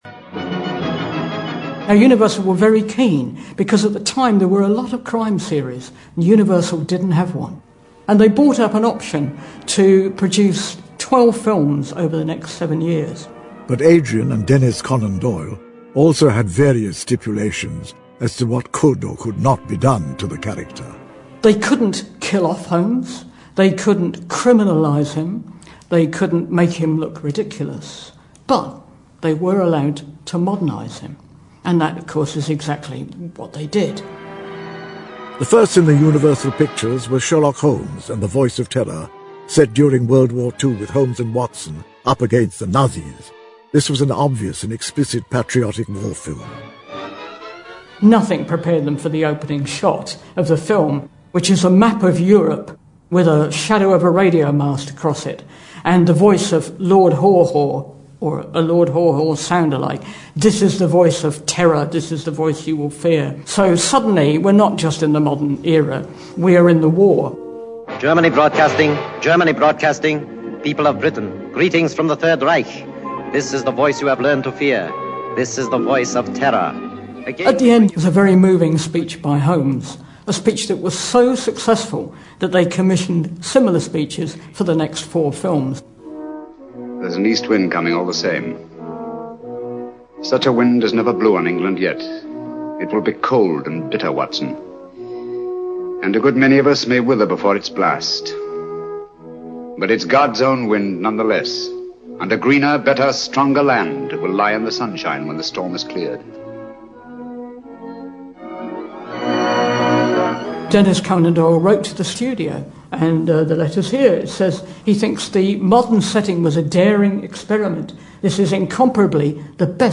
在线英语听力室如何成为福尔摩斯 第11期的听力文件下载, 《如何成为福尔摩斯》栏目收录了福尔摩斯的方法，通过地道纯正的英语发音，英语学习爱好者可以提高英语水平。